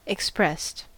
Ääntäminen
Ääntäminen US Haettu sana löytyi näillä lähdekielillä: englanti Käännöksiä ei löytynyt valitulle kohdekielelle. Expressed on sanan express partisiipin perfekti.